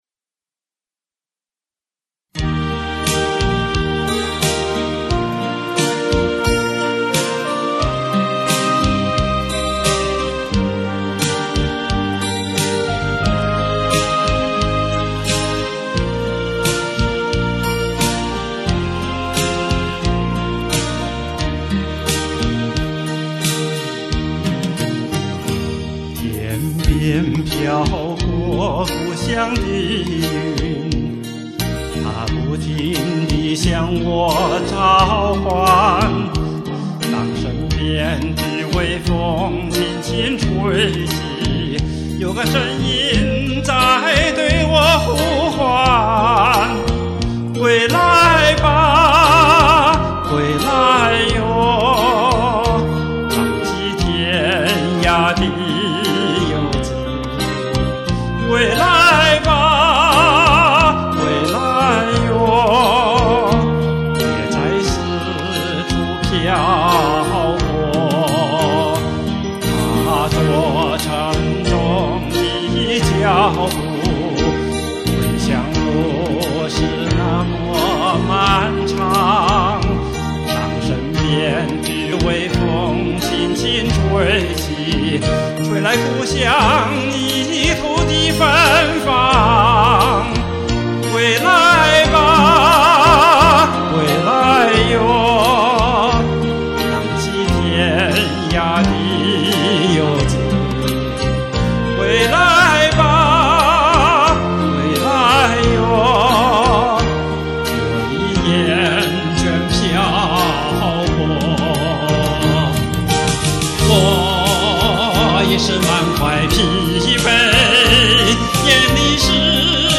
不好唱.
可惜功力不足.